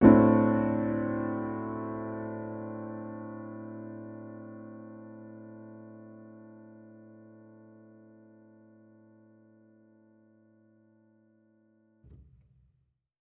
Index of /musicradar/jazz-keys-samples/Chord Hits/Acoustic Piano 2
JK_AcPiano2_Chord-Am13.wav